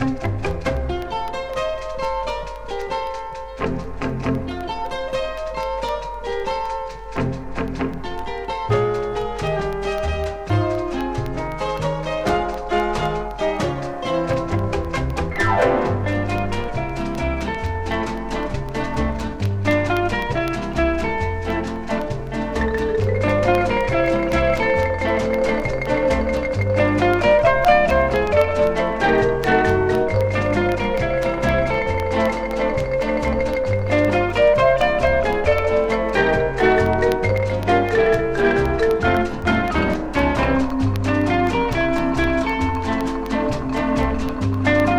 小気味良いアレンジに多種多様な楽器演奏、音の粒立ちの良さと奥行きあるサウンドに耳も心も嬉しくなります。
Jazz, Latin, Easy Listening　USA　12inchレコード　33rpm　Stereo